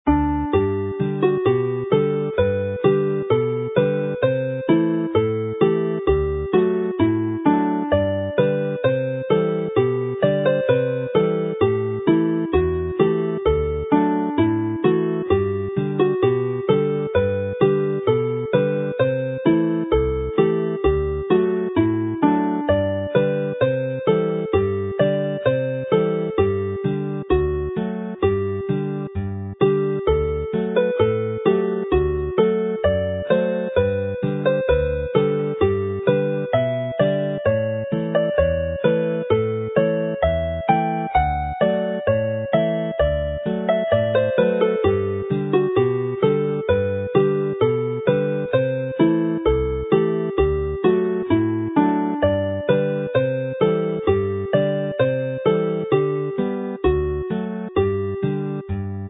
Agoriad y Blodau is a beautiful lyrical piece which is played in dance time here; it is attributed to John Roberts, Telynor Cymru, born in 1816.